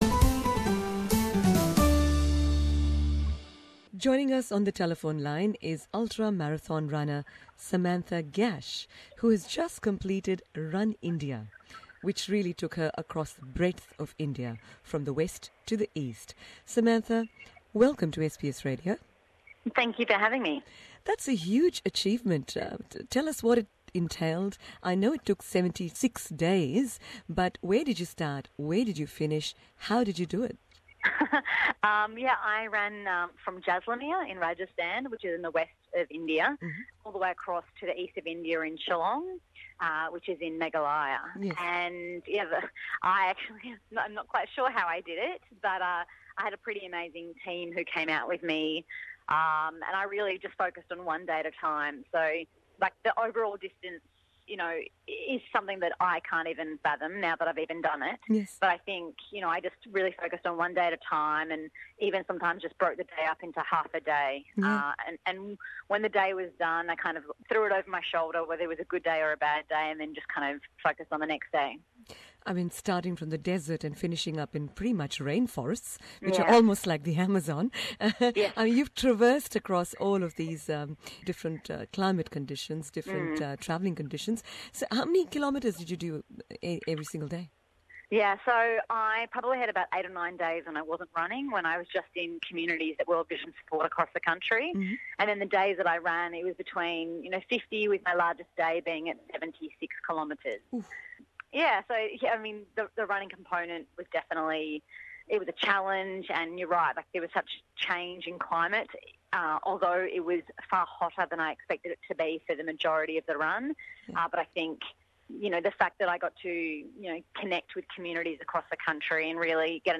Do hear our full interview with her and view these unforgettable images that were captured along the way.